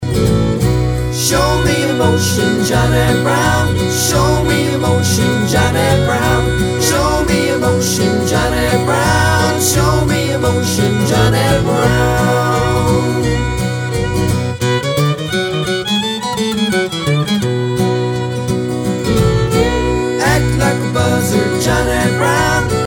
Traditional/Georgia Sea Islands